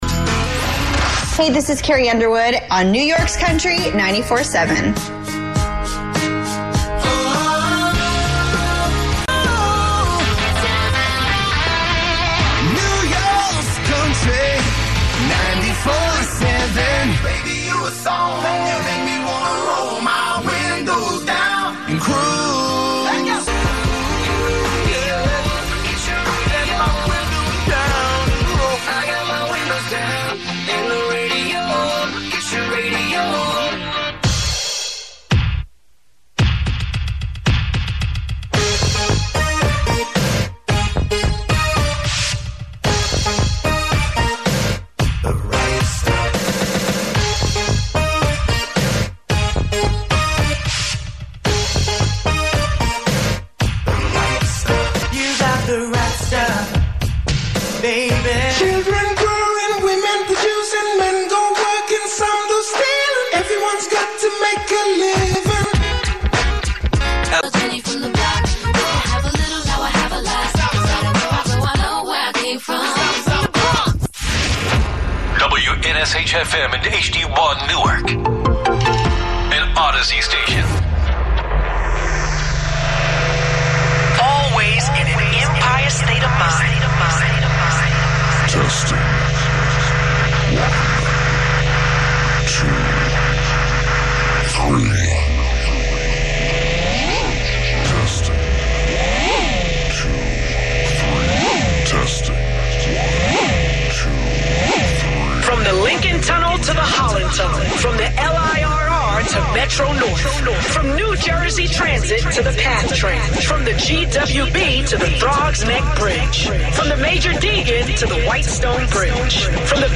New Format: Classic Hip Hop “94.7 The Block” WXBK